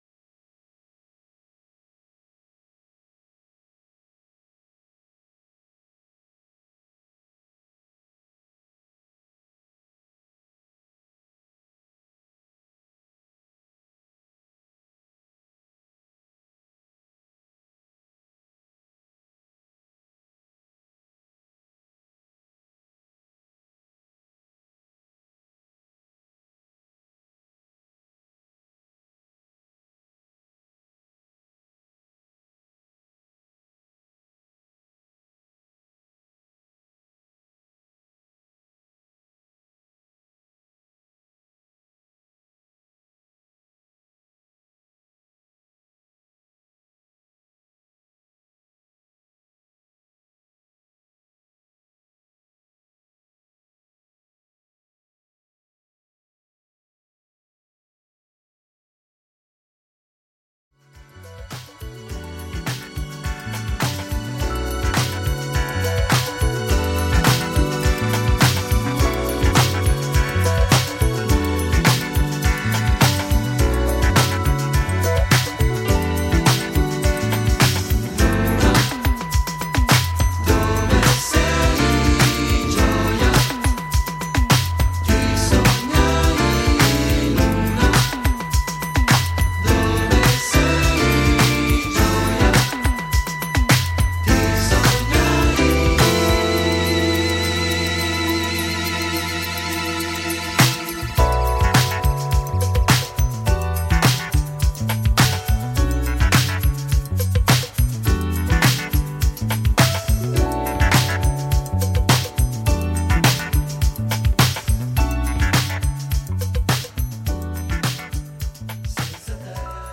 Italian trio
eclectic Jazz Funk Flavour